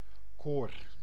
Ääntäminen
Synonyymit zangkoor rei Ääntäminen Tuntematon aksentti: IPA: /ˈkor/ Haettu sana löytyi näillä lähdekielillä: hollanti Käännös Ääninäyte Substantiivit 1. vocal ensemble 2. choir UK 3. chorus US Suku: n .